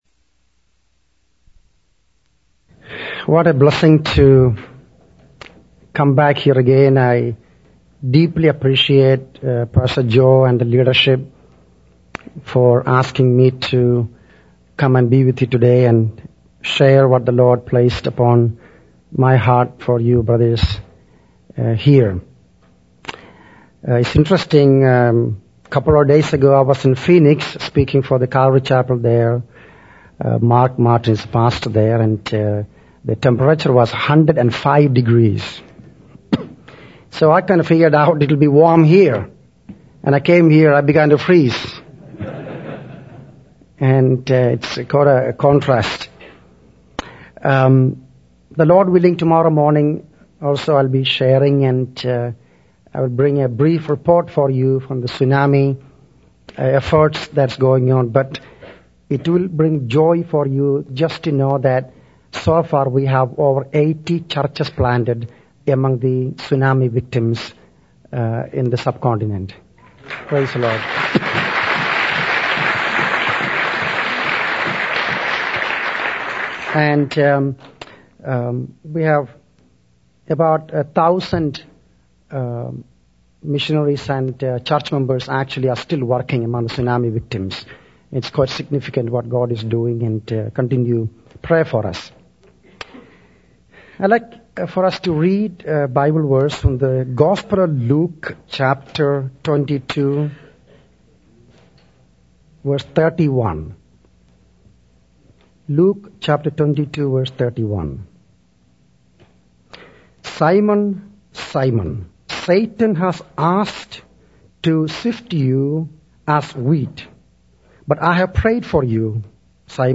In this sermon, the speaker shares his experience of preparing for a year-end conference in Ajmer, India.